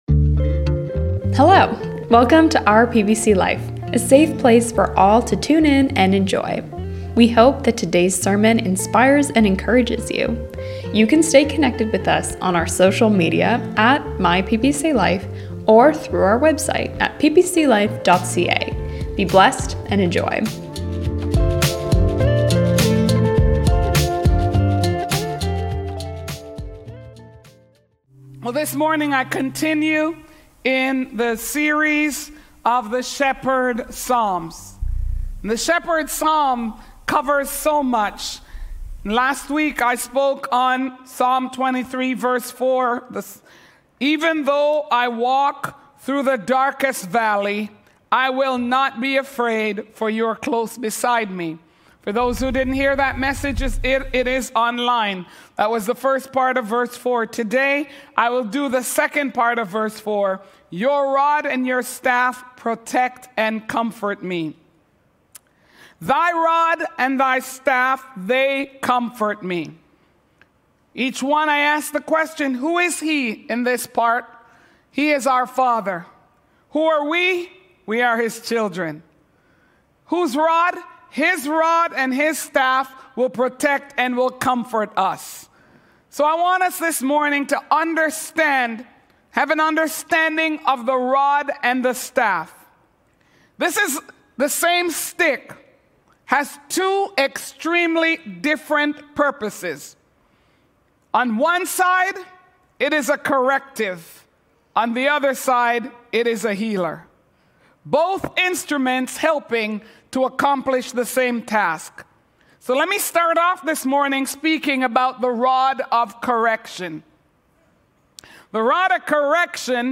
continuing in our summer sermon series 'Times of Refreshing'